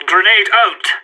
CSGO Grenade Out Sound Effect Free Download